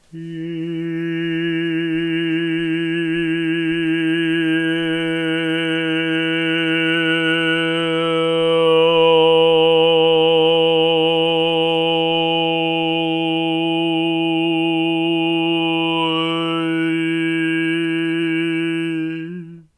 10b_vowel_sequence.ogg